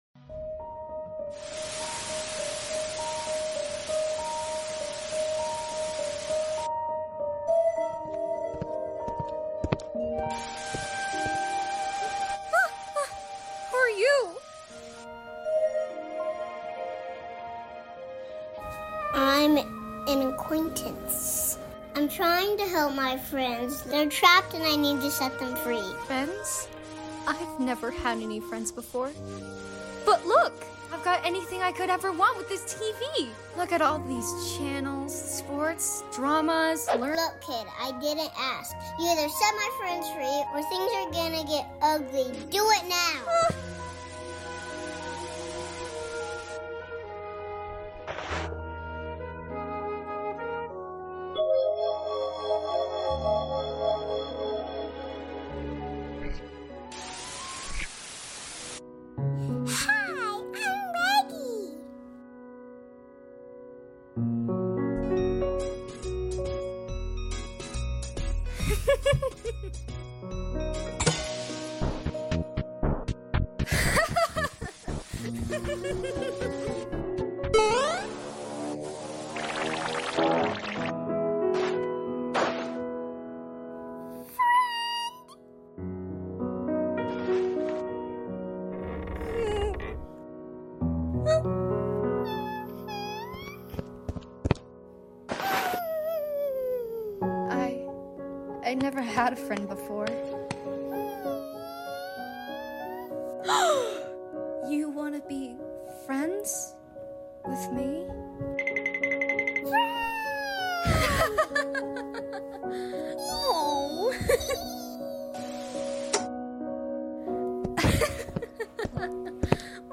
Another voice over I did sound effects free download